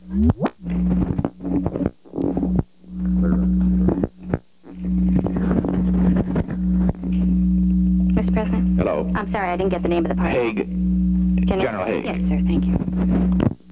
Secret White House Tapes
Location: White House Telephone
The White House operator talked with the President.